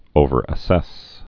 (ōvər-ə-sĕs)